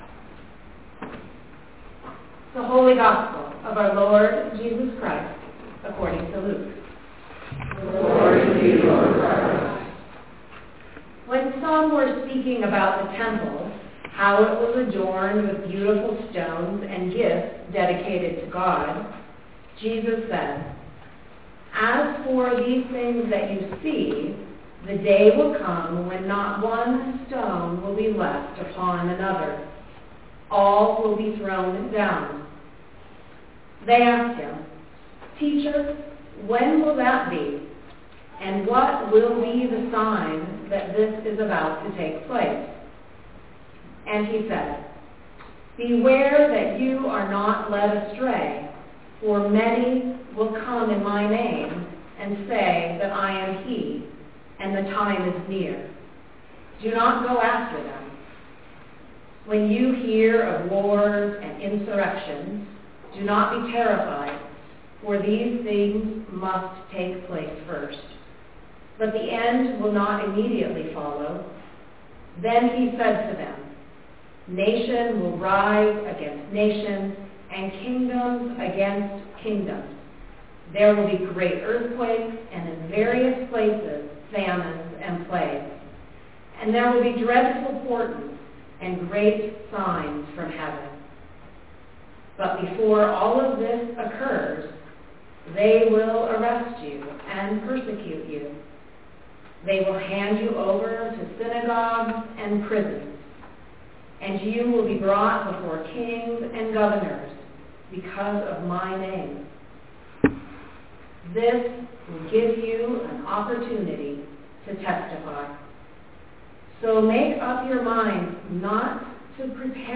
Our gospel Luke 21:5-19 is linked here and included in the recording.